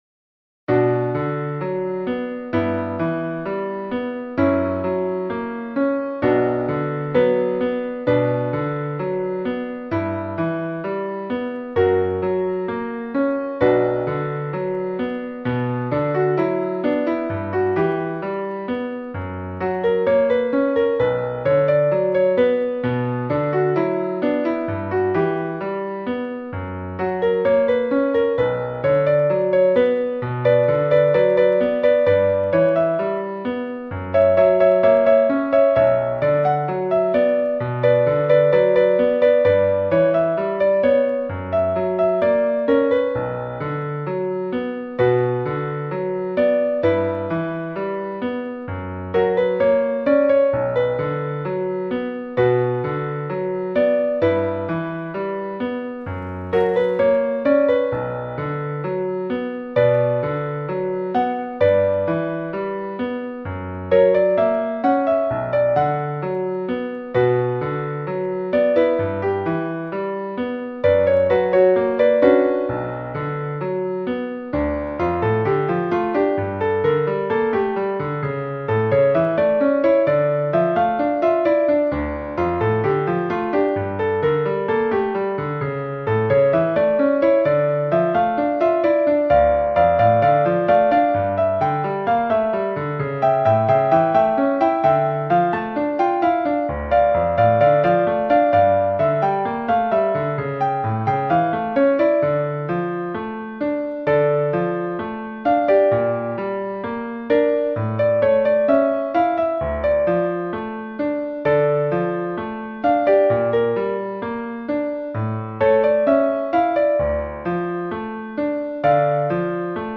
Ноты для фортепиано.